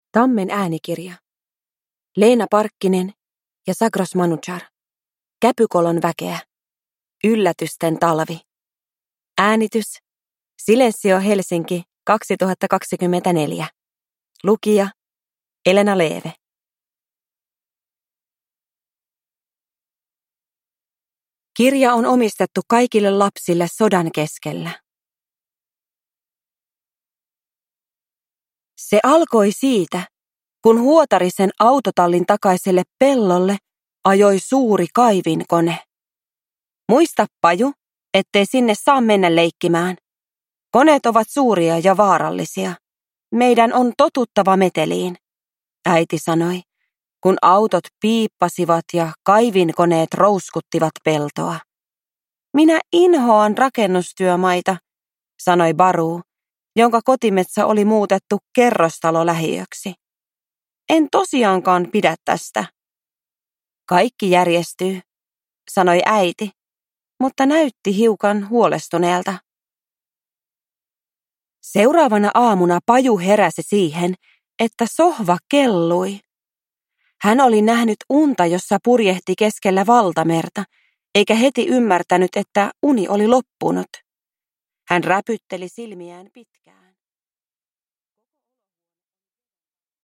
Käpykolon väkeä. Yllätysten talvi – Ljudbok
Uppläsare: Elena Leeve